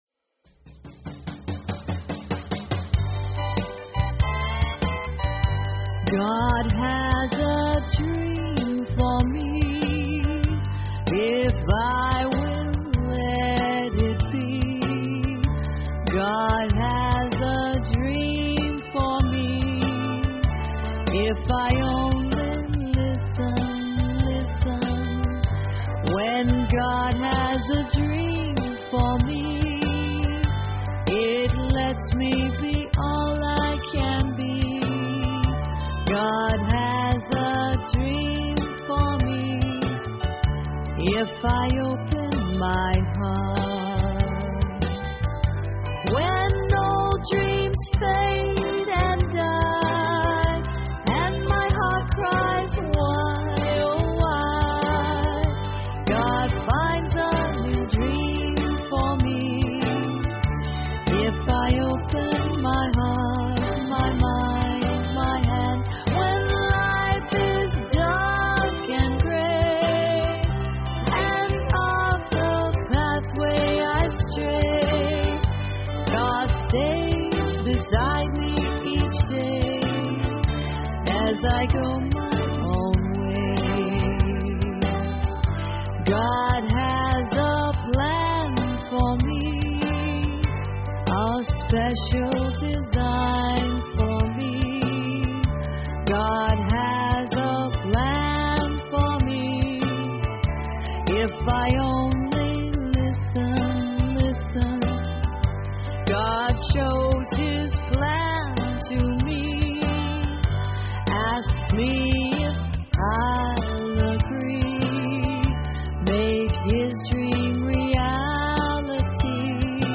Talk Show Episode, Audio Podcast, Inner_Garden_Online_Chapel and Courtesy of BBS Radio on , show guests , about , categorized as